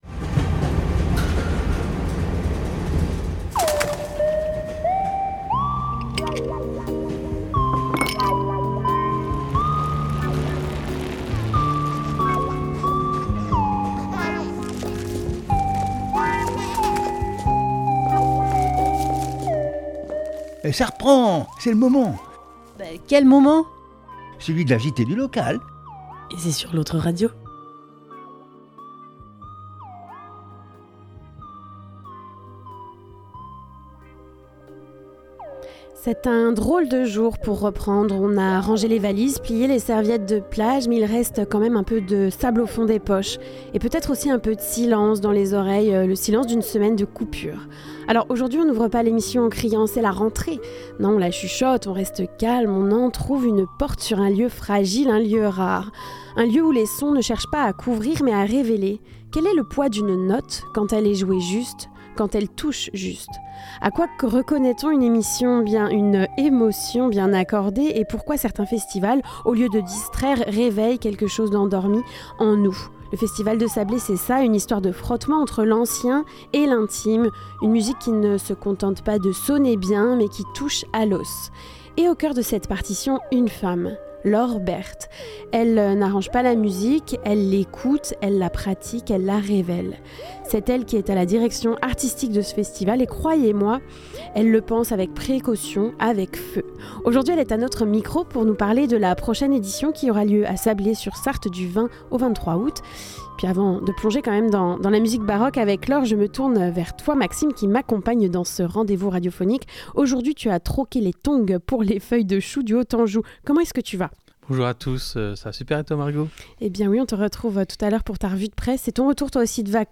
INTW-Festival-de-Sable-25-lautre-radio.mp3